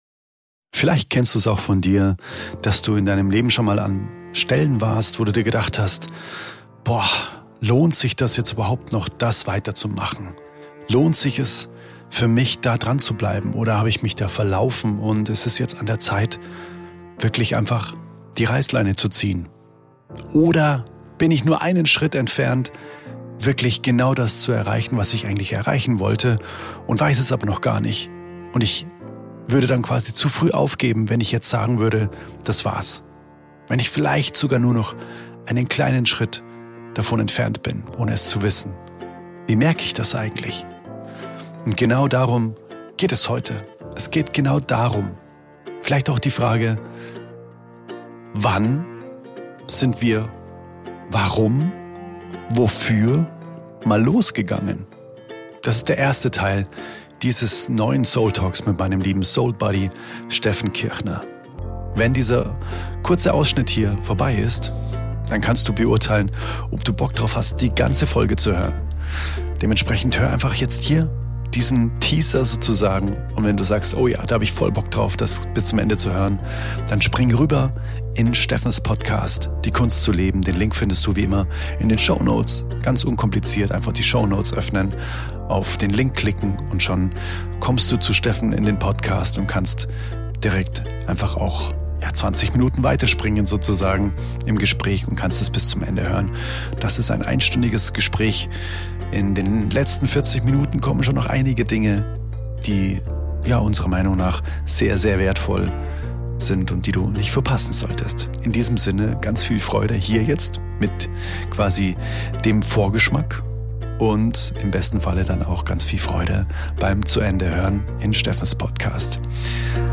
[SOULTALK] Dranbleiben oder loslassen? | Gespräch